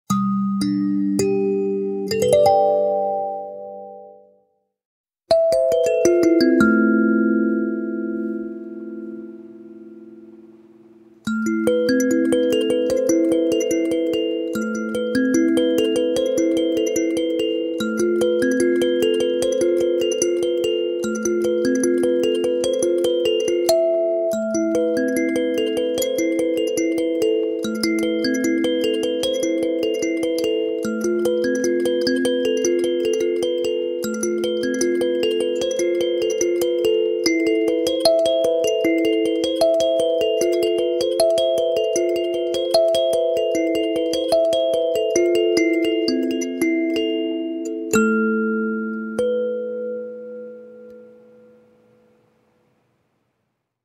Kalimba • 9 notes • La mineur
Le kalimba est un instrument intuitif, idéal pour s’initier à la musique et profiter de mélodies douces et apaisantes. Grâce à son accordage en La Mineur, il offre une harmonie naturelle et fluide, permettant de jouer librement sans fausse note.
• 9 lamelles parfaitement accordées en La Mineur
• Son envoûtant, idéal pour la détente et la sonothérapie
• Bois de qualité, offrant une belle résonance naturelle
Kalimba-9-notes-La-mineur.mp3